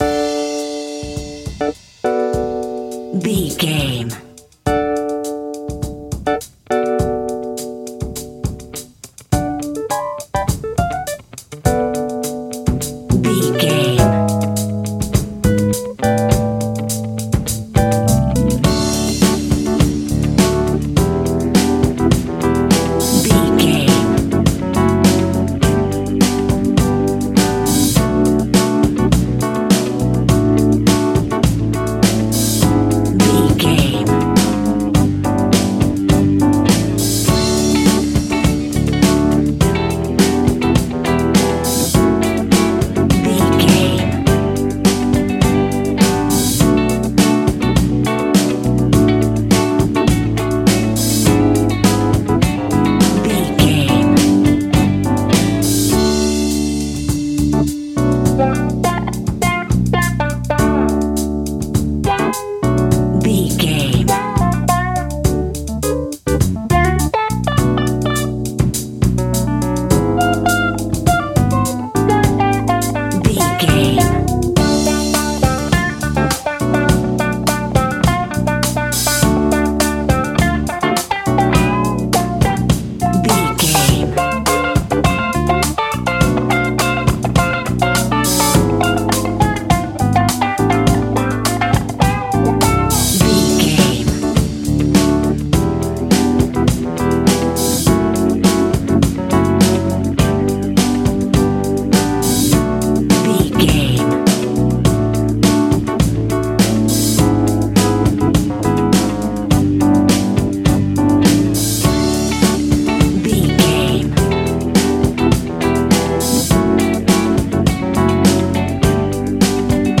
Ionian/Major
funky
uplifting
bass guitar
electric guitar
organ
drums
saxophone
groovy